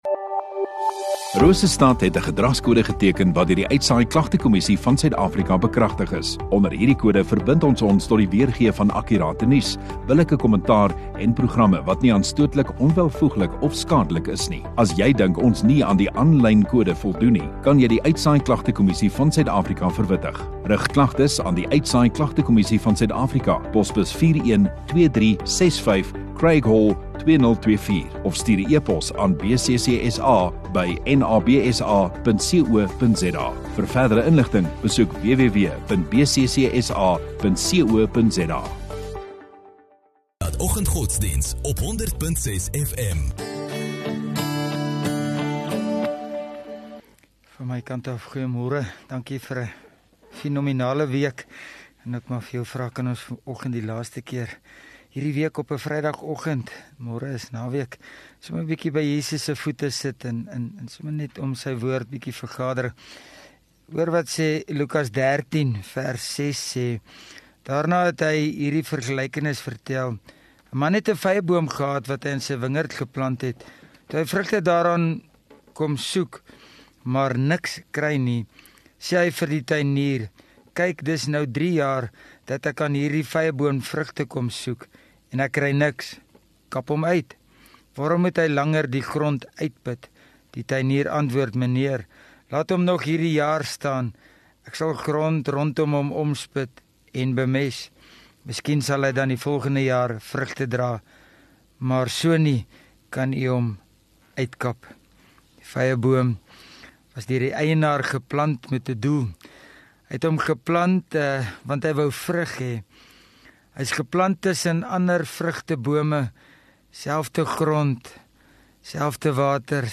20 Feb Vrydag Oggenddiens